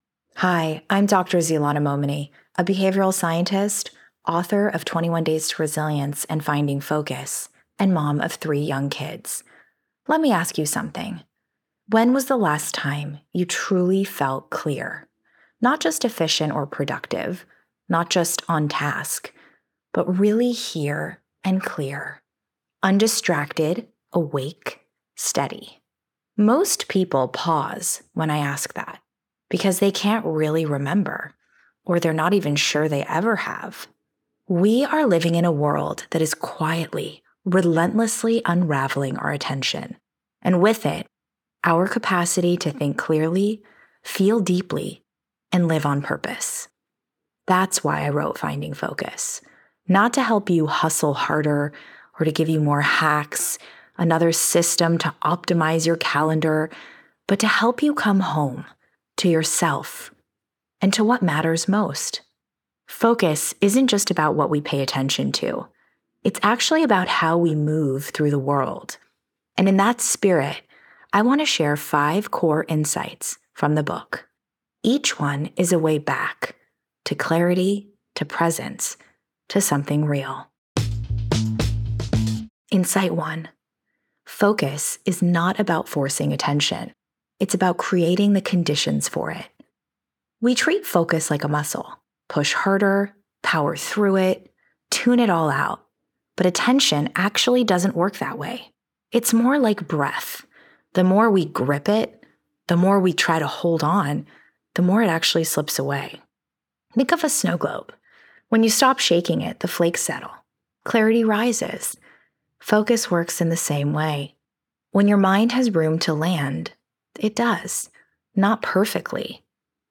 Enjoy our full library of Book Bites—read by the authors!—in the Next Big Idea App: